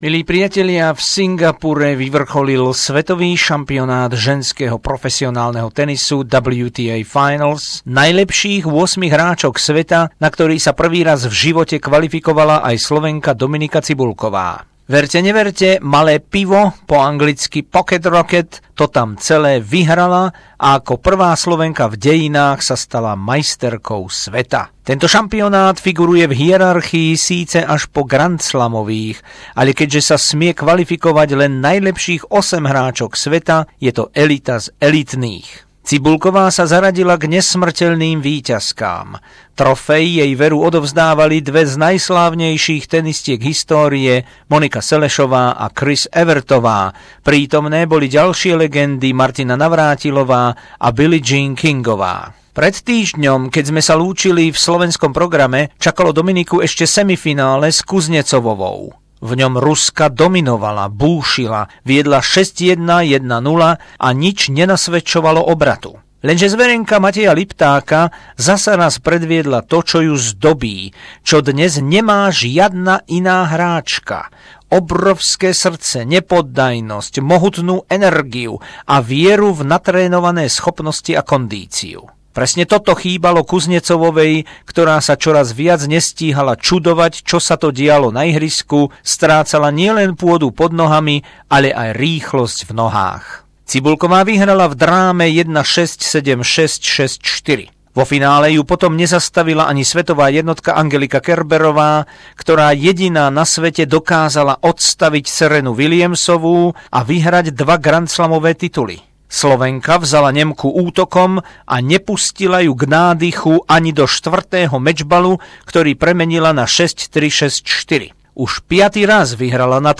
Doplnené o rozhovor